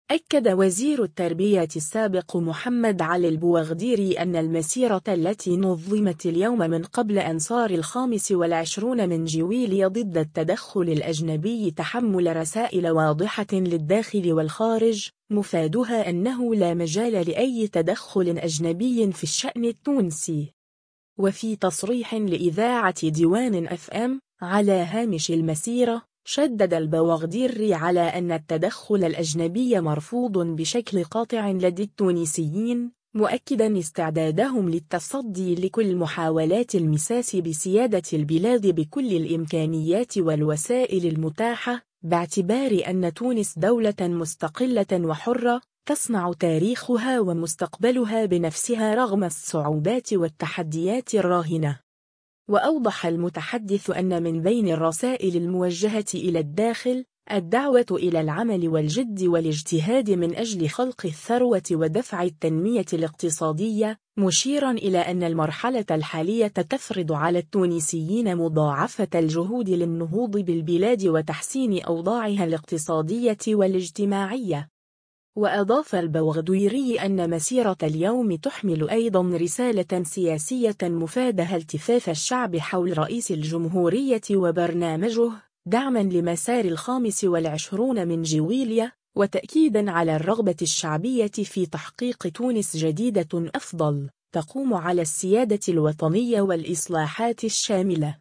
وفي تصريح لإذاعة ديوان أف أم، على هامش المسيرة، شدّد البوغديري على أن التدخل الأجنبي مرفوض بشكل قاطع لدى التونسيين، مؤكداً استعدادهم للتصدي لكل محاولات المساس بسيادة البلاد بكل الإمكانيات والوسائل المتاحة، باعتبار أن تونس دولة مستقلة وحرة، تصنع تاريخها ومستقبلها بنفسها رغم الصعوبات والتحديات الراهنة.